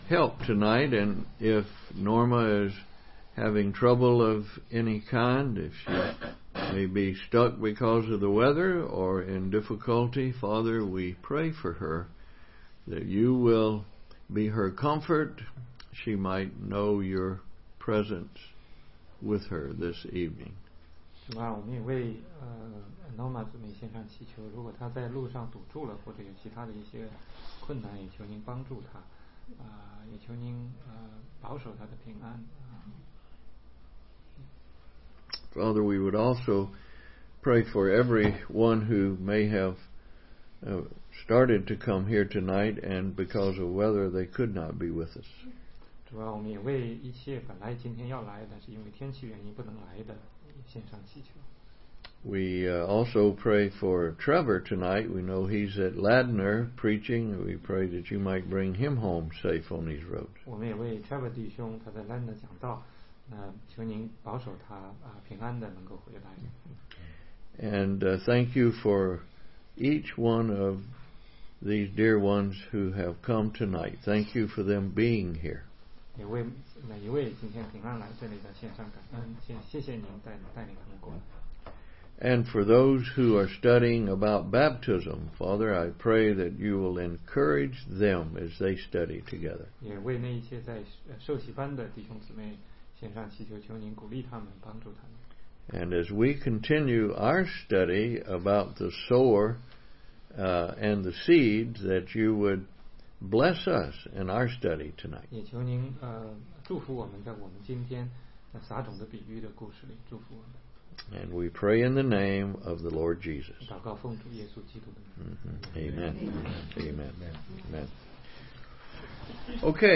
16街讲道录音 - 圣灵的果子